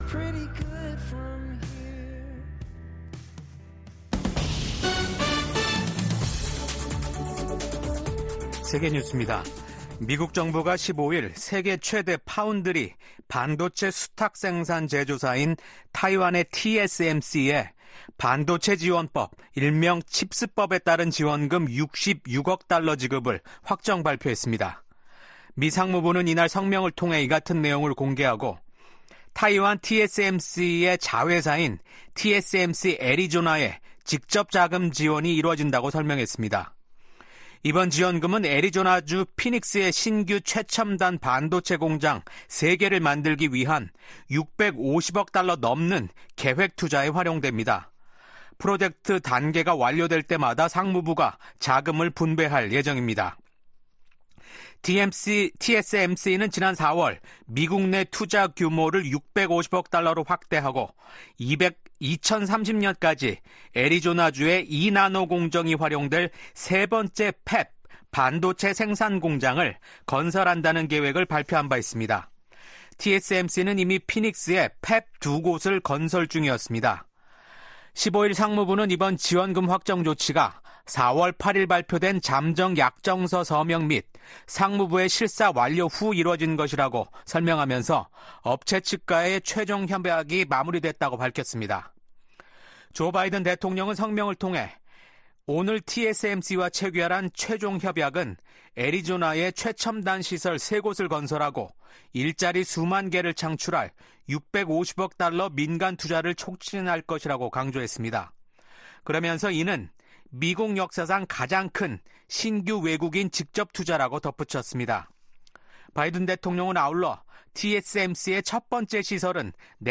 VOA 한국어 아침 뉴스 프로그램 '워싱턴 뉴스 광장'입니다. 미국 국무장관이 한국, 일본, 캐나다 외교장관 등과 잇따라 만나 북한군 파병 문제를 논의했습니다. 김정은 북한 국무위원장은 석달 만에 또 자폭 공격형 무인기 성능시험 현지지도에 나서 이 무인기의 본격적인 대량생산을 지시했습니다.